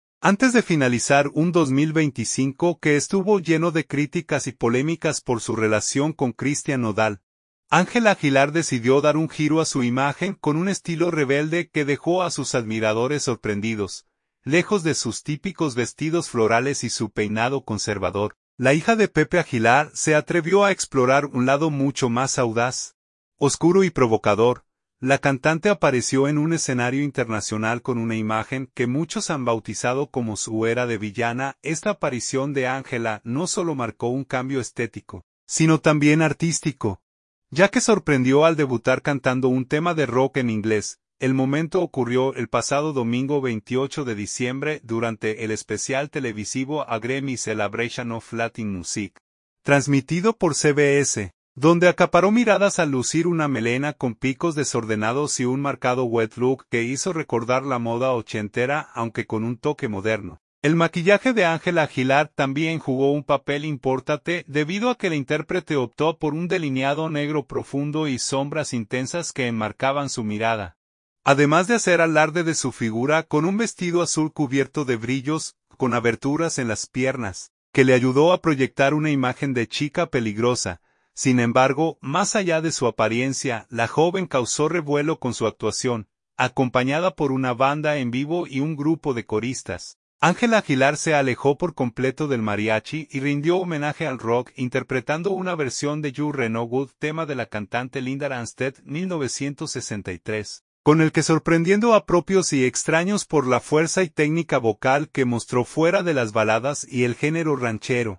Acompañada por una banda en vivo y un grupo de coristas